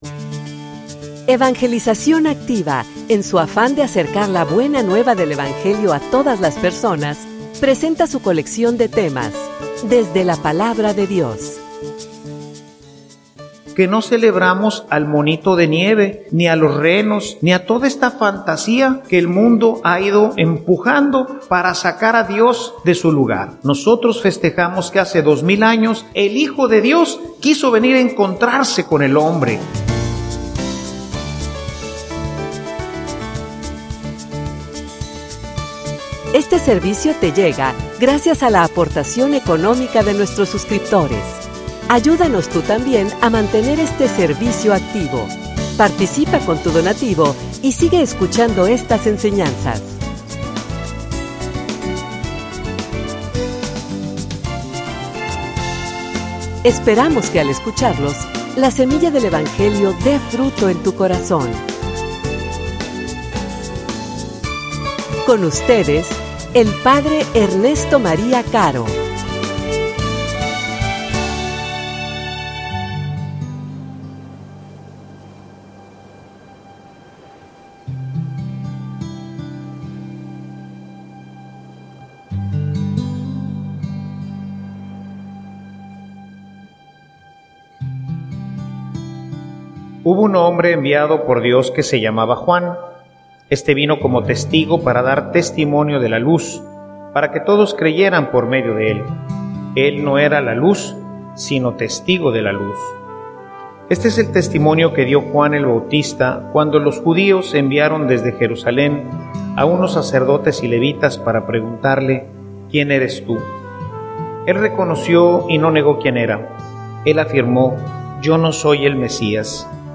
homilia_Instrumentos_para_el_encuentro.mp3